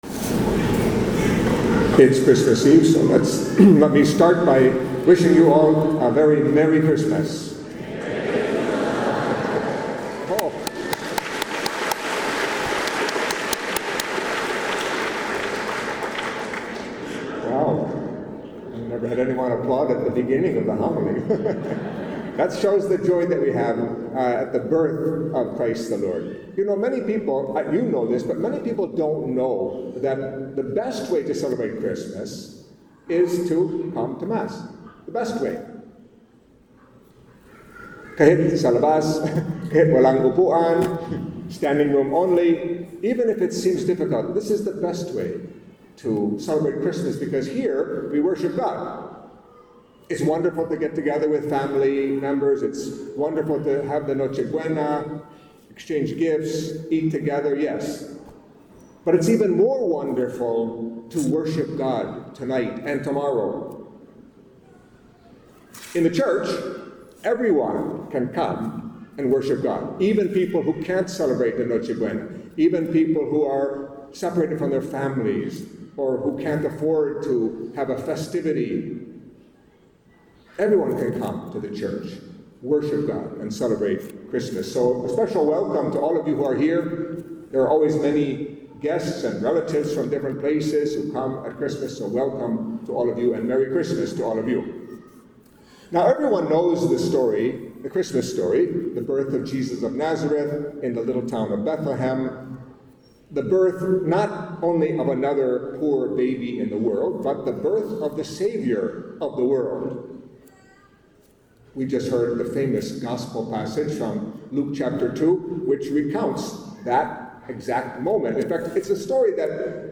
Catholic Mass homily for Nativity of the Lord (Christmas)